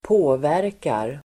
Uttal: [²p'å:vär:kar]